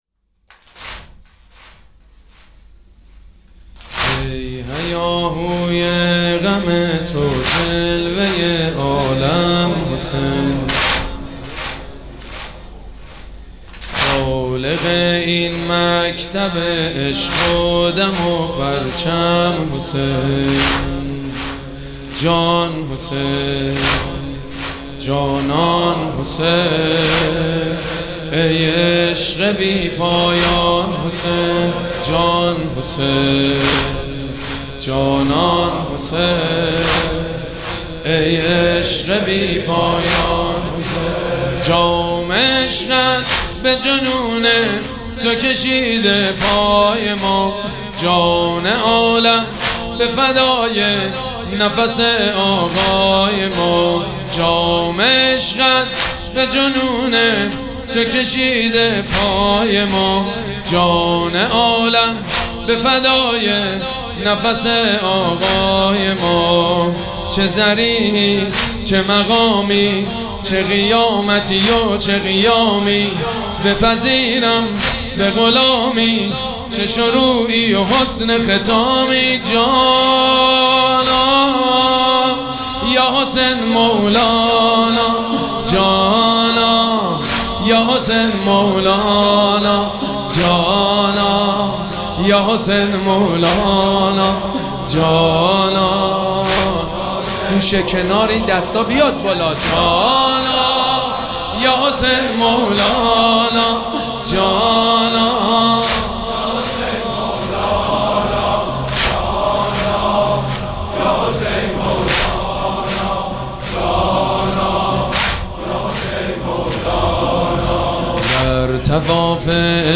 مراسم عزاداری شب چهارم محرم الحرام ۱۴۴۷
مداح
حاج سید مجید بنی فاطمه